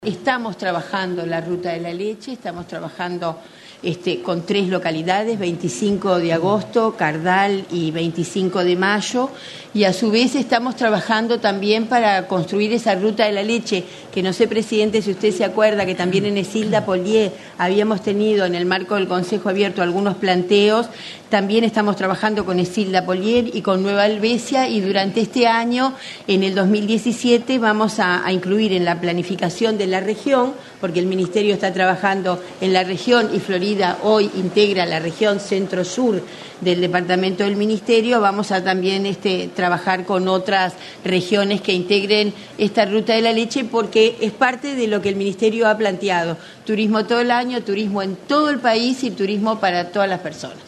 El Ministerio de Turismo anunció que trabaja para que en 2017 se pueda consolidar la Ruta de la Leche que integrarán las localidades de 25 de Agosto, 25 de Mayo, Cardal, Ecilda Paullier y Nueva Helvecia, dijo la ministra Liliam Kechichian en el Consejo de Ministros abierto en Florida.